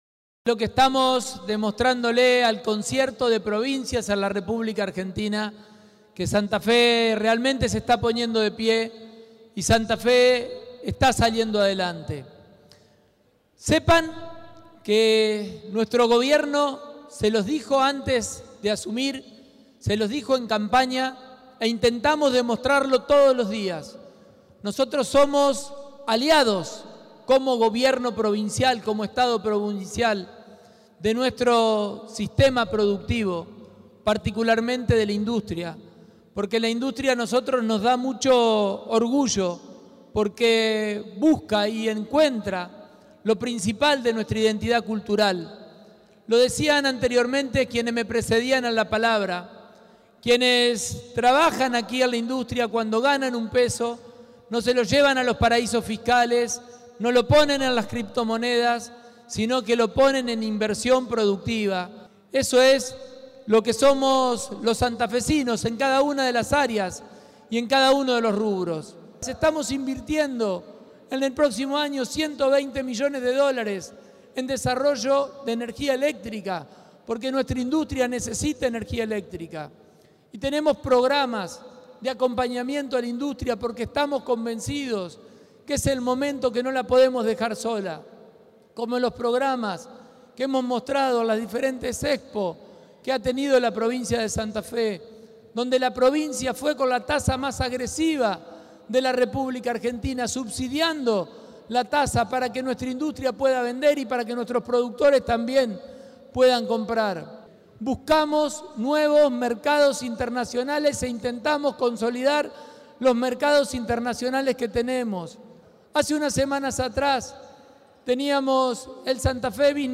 El gobernador Maximiliano Pullaro participó este viernes en Las Parejas del tradicional almuerzo que organiza la Federación Industrial de Santa Fe (Fisfe) con motivo del Día de la Industria.
Pullaro - Scaglia